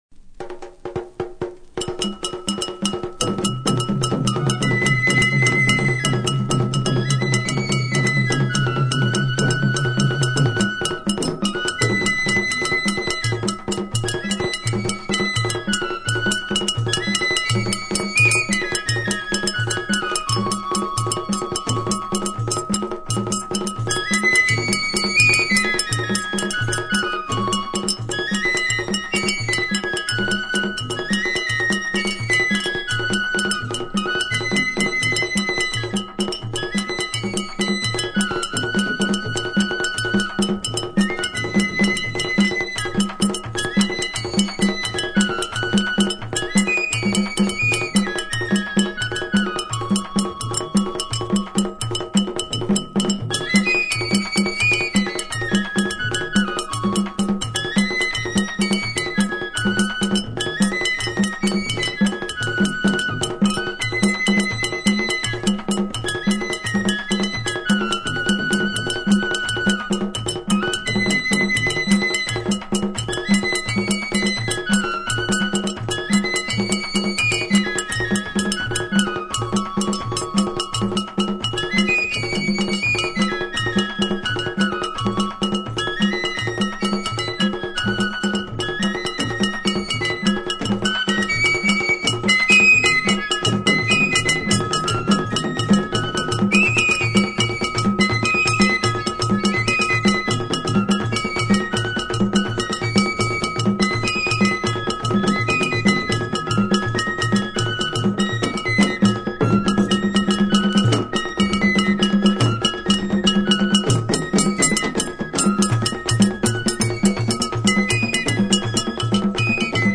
Contre danse flute simple
2022 CONTRE DANSE (VIEUX GRIS) FLOKLORE HAITIEN audio closed https
contre-danse-flute.mp3